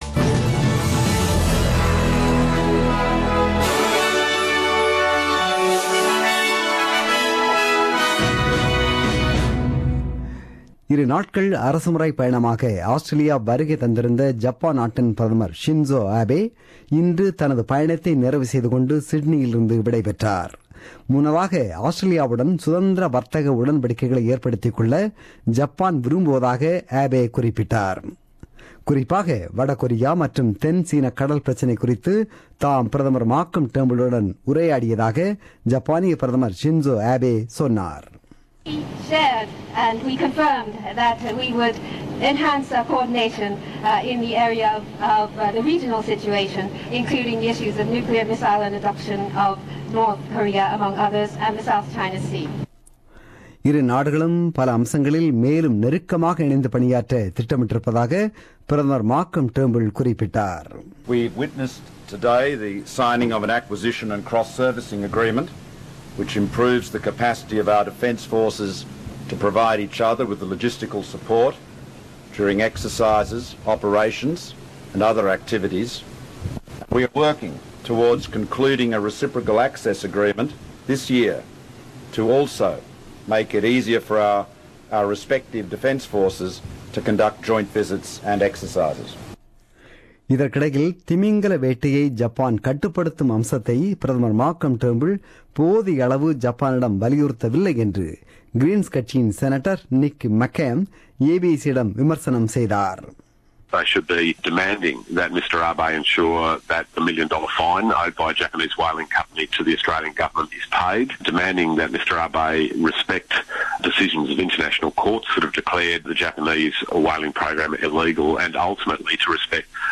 The news bulletin broadcasted on 15 January 2017 at 8pm.